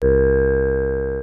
HOHNER 1982 2.wav